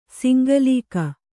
♪ singalīka